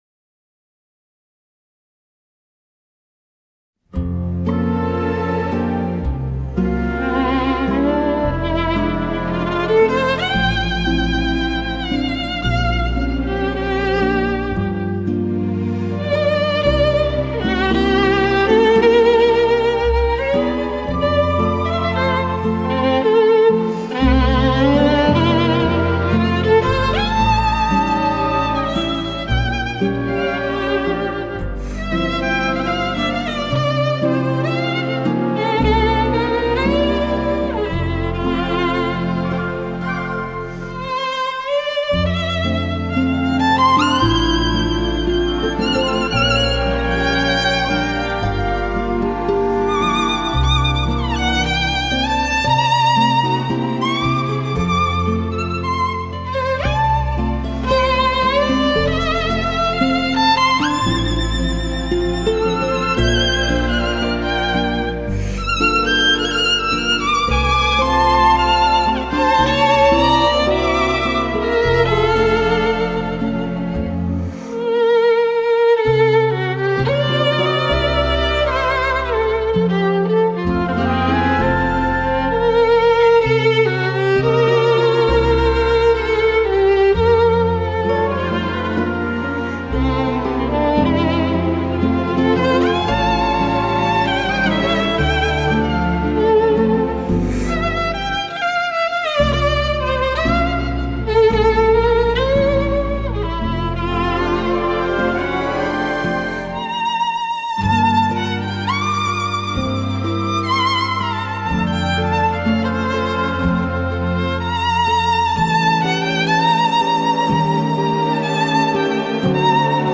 Arr. For Violin and Orchestra
Classical, Crossover